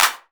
6CLAPS.wav